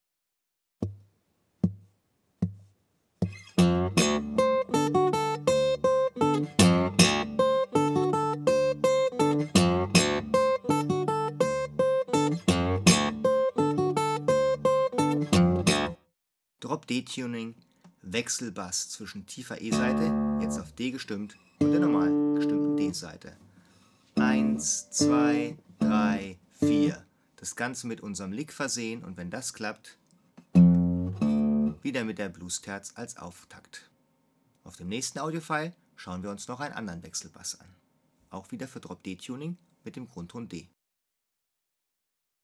Lese- / Hörproben zu Garantiert Akustik Bluesgitarre Lernen
sound_clipping 189 – Dropped D-Tuning, D Wechselbass Oktave
189-Dropped-D-Tuning-D-Wechselbass-Oktave.mp3